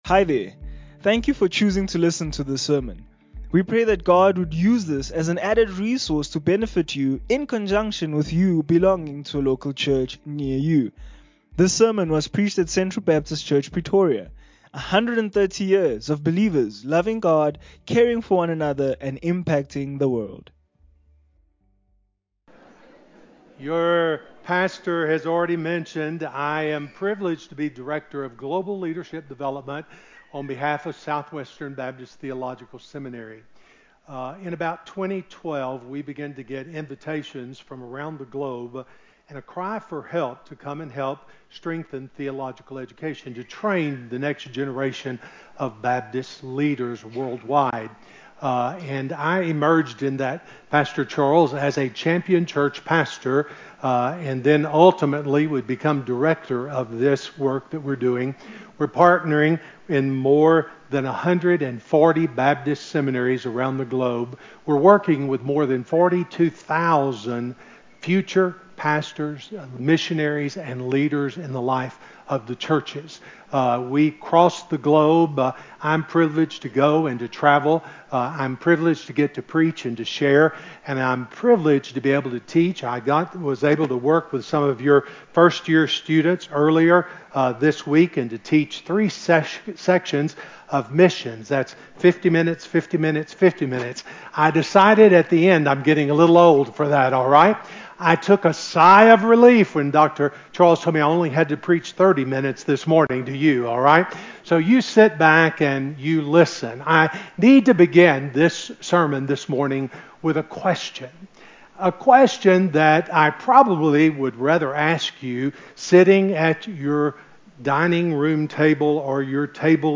Sermons - Central Baptist Church Pretoria